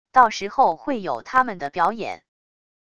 到时候会有他们的表演wav音频生成系统WAV Audio Player